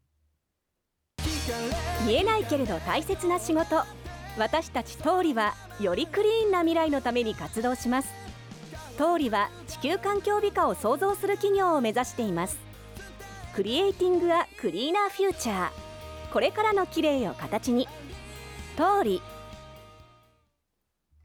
また、月・水・金曜日の午前中にもCMが流れます。